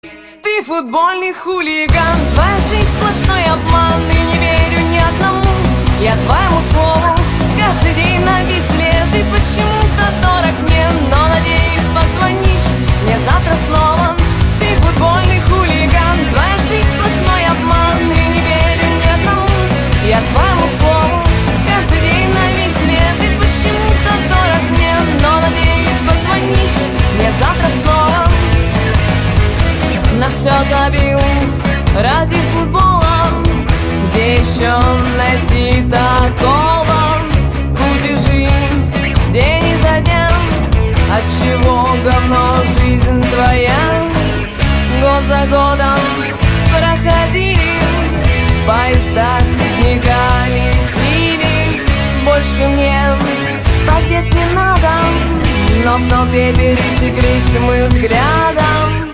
• Качество: 160, Stereo
громкие
женский вокал
русский рок
punk rock